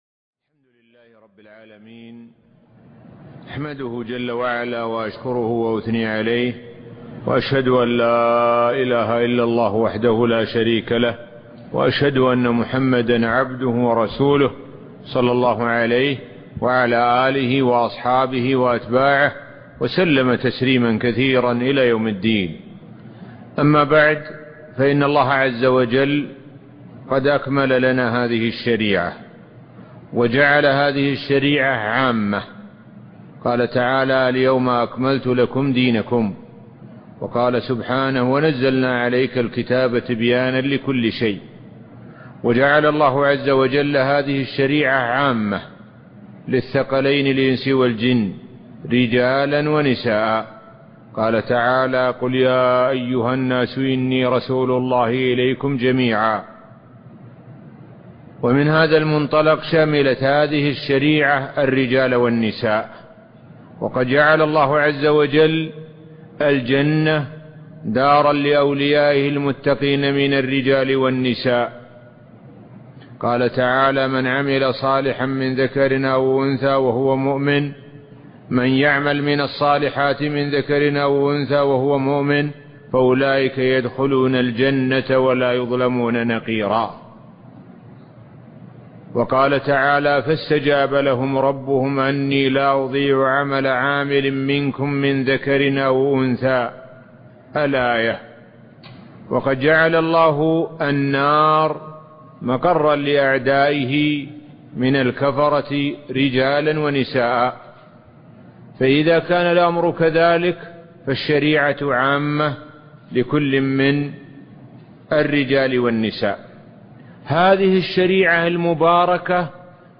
محاضرة - المرأة ما لها وما عليها